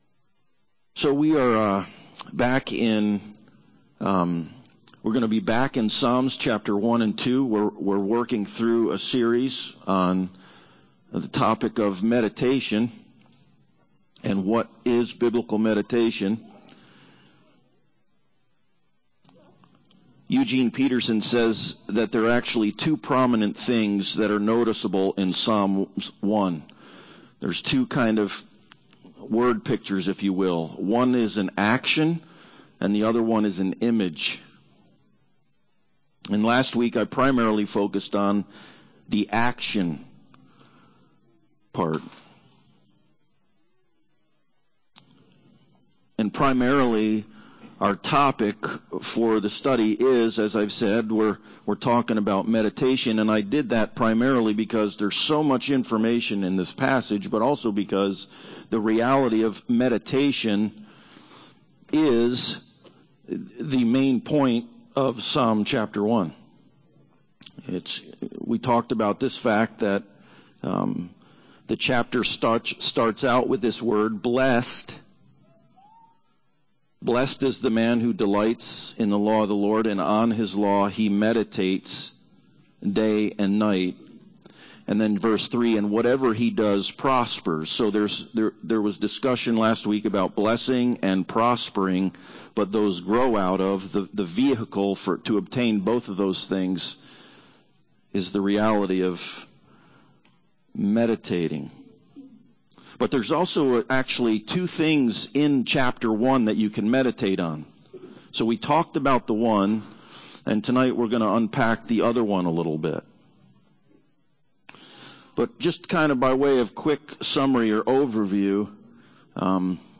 Meditation Passage: Psalm 1:1-6 Service Type: Sunday Service Bible Text